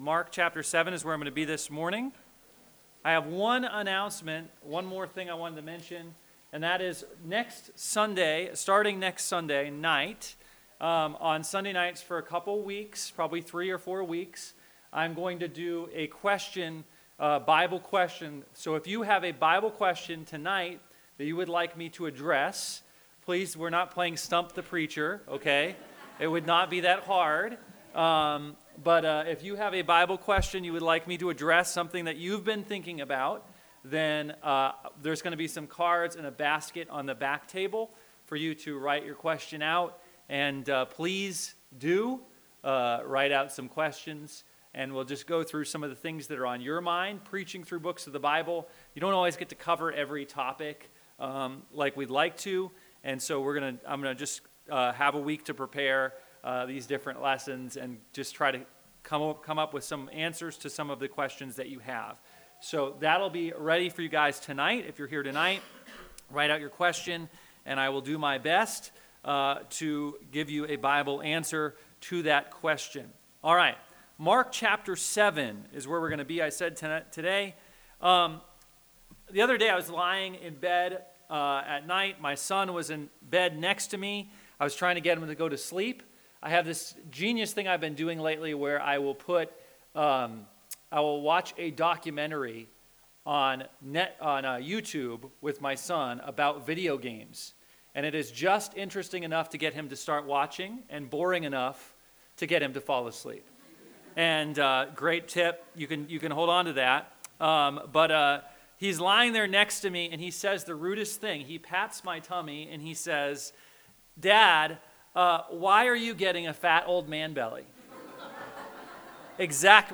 Sermons from Bible Baptist Church